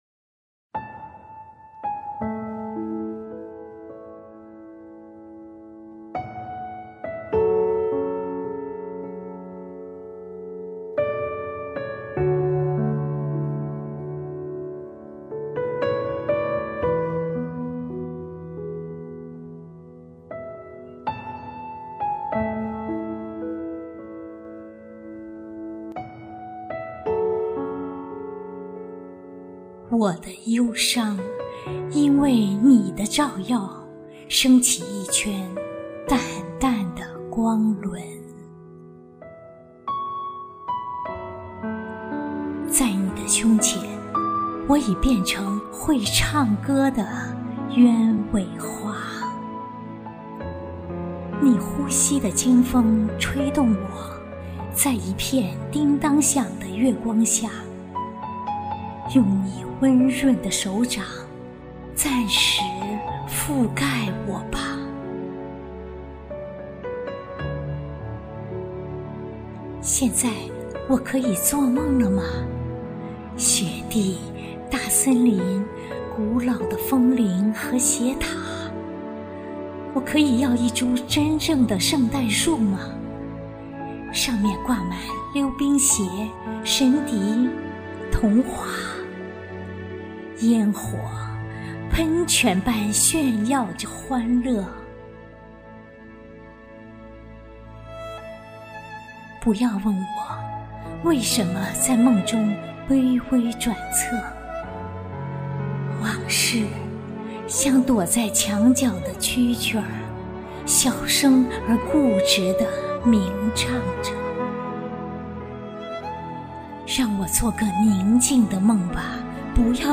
清新悦耳，娓娓动听~~